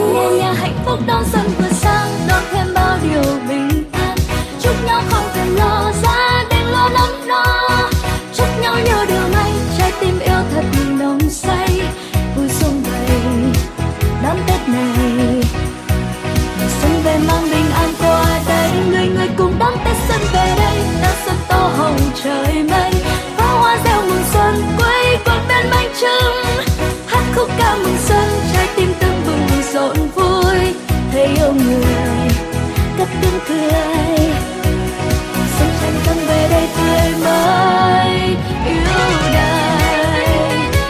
Nhạc chuông 3 lượt xem 13/03/2026